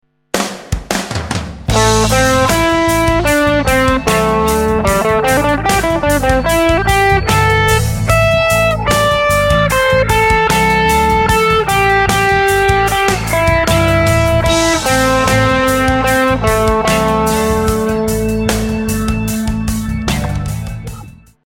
Listen to the following short solo using the A minor pentatonic scale with these missing elements to hear how unappealing it can sound.
Un-Sexy Solo